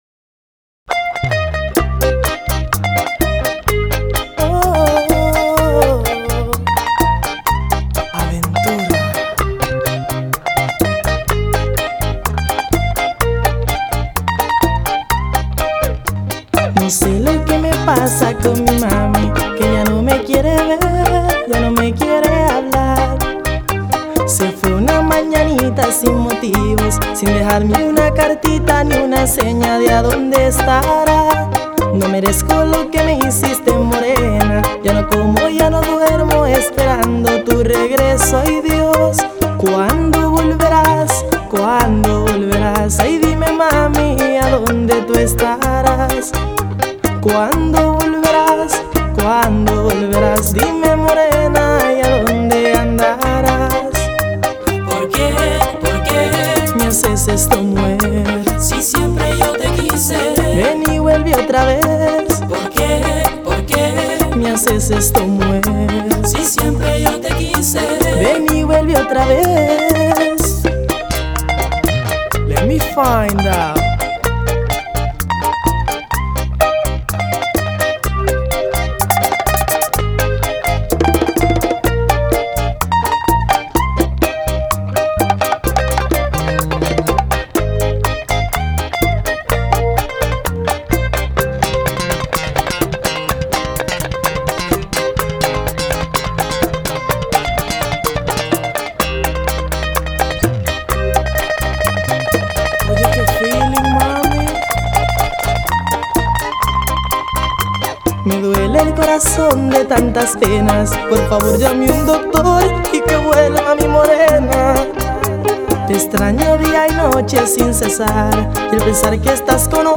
• Категория:Бачата